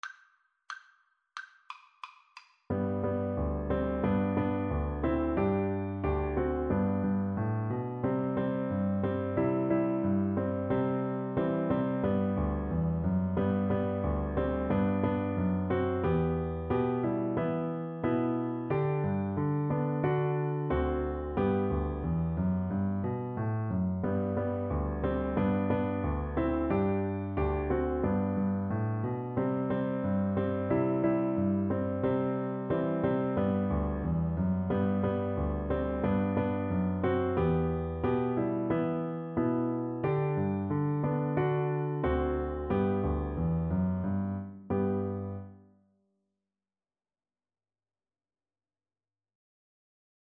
G major (Sounding Pitch) (View more G major Music for Viola )
Steady two in a bar = c. 90
2/2 (View more 2/2 Music)